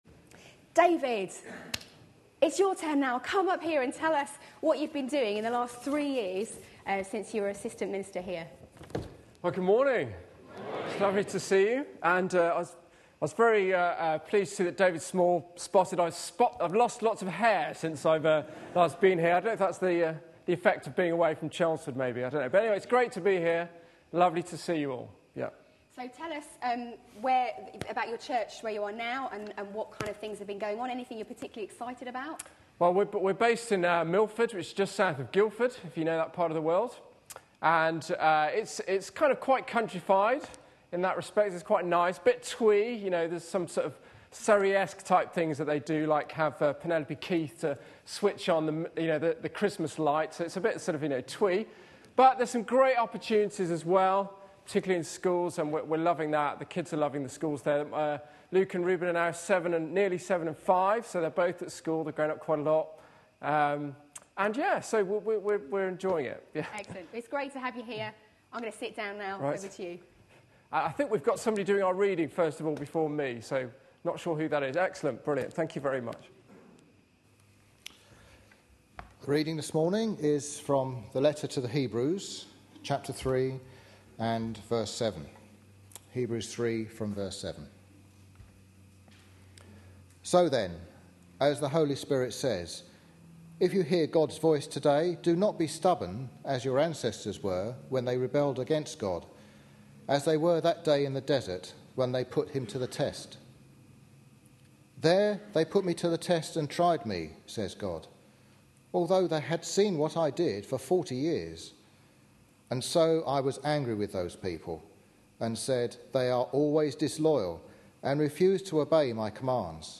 A sermon preached on 7th October, 2012.